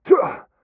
m_pain_12.ogg